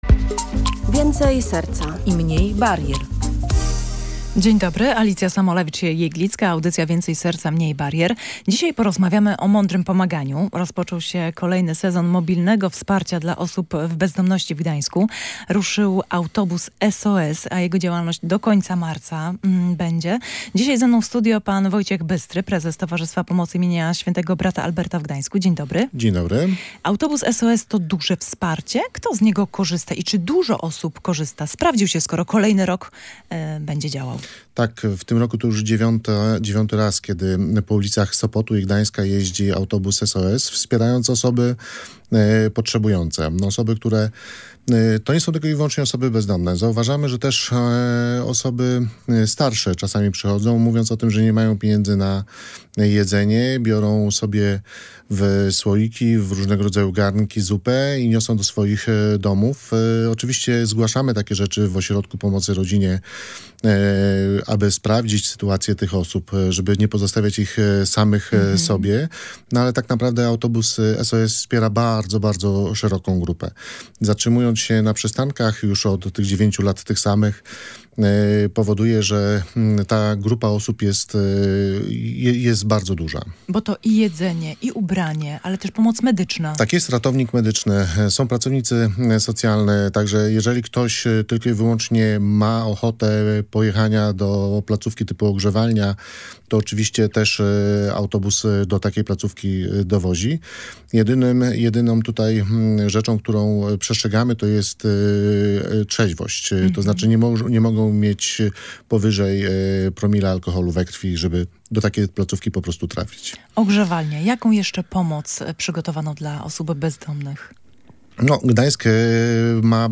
Jak mądrze pomagać osobom w kryzysie bezdomności? Posłuchaj rozmowy z ekspertem